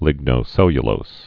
(lĭgnō-sĕlyə-lōs)